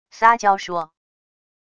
撒娇说wav音频生成系统WAV Audio Player